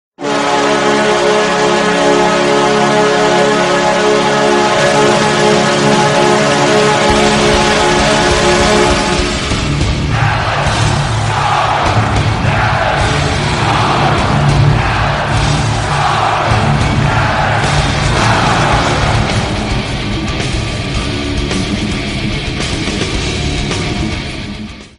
dallas stars 2020 goal horn sound effects
dallas-stars-2020-goal-horn